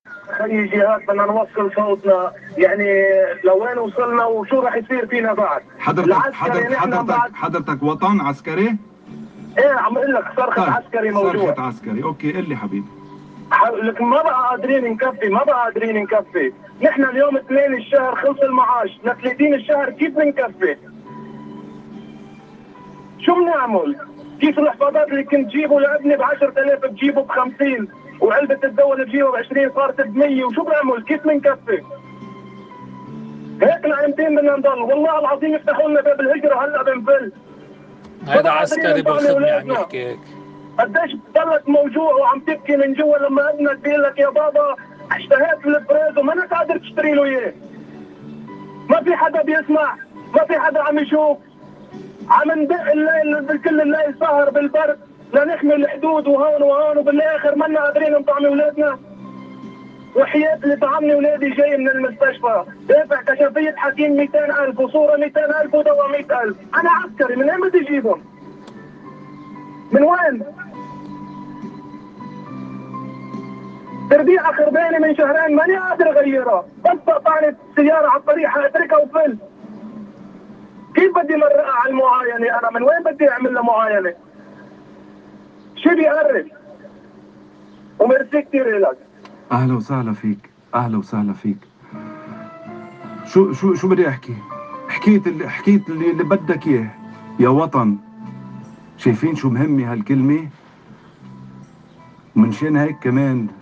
اتصال عسكري على اذاعة صوت لبنان